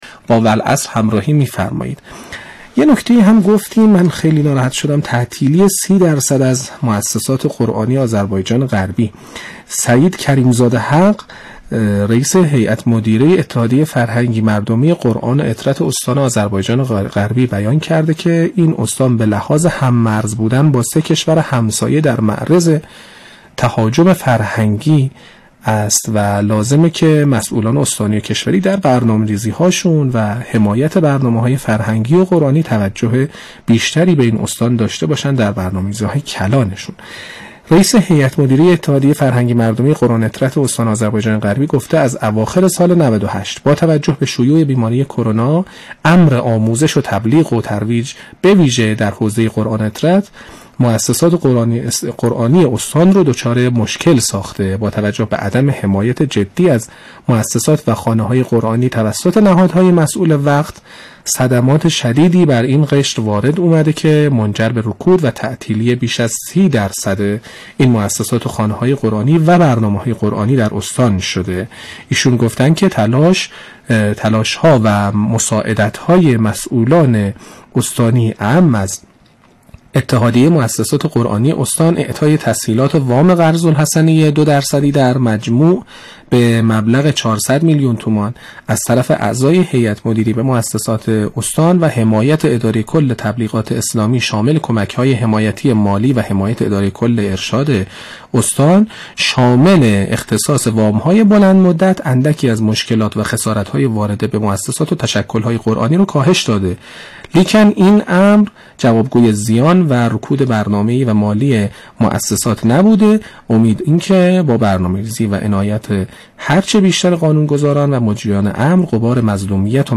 برنامه رادیویی «والعصر» با هدف بررسی و تحلیل اخبار امور قرآنی روزانه یا هفته به روی آنتن می‌رود.
برنامه والعصربه گزارش ایکنا، والعصر، برنامه عصرگاهی رادیو قرآن به صورت زنده حوالی ساعت 14:30، اخبار و رویدادهای قرآنی را در ایام هفته مورد بررسی و تجزیه تحلیل قرار می‌دهد.